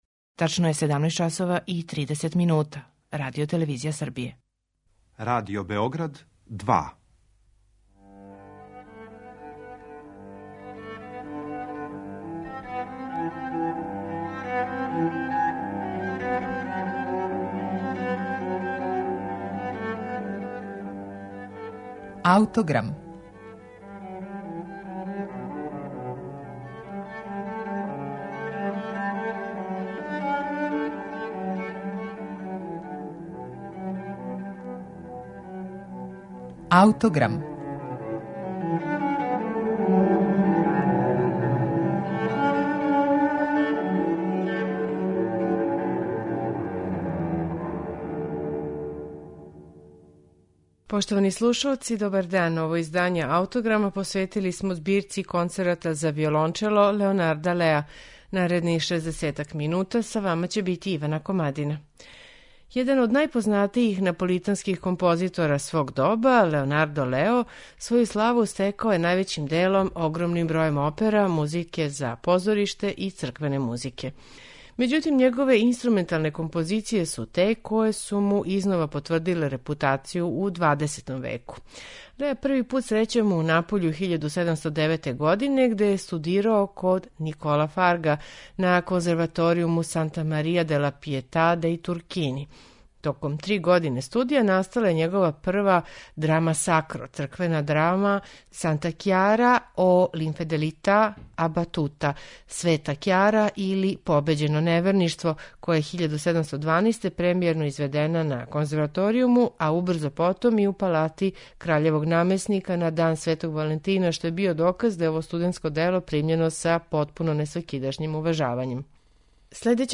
Слушаћете концерте за виолончело и оркестар Леонарда Леа
Велики део заслуге за то припада његовој збирци шест концерата за виолончело и оркестар којој смо посветили данашњи Аутограм. Године 1737, када су ови концерти настали, избор виолончела као солистичког инстурмента био је више него изненађујући.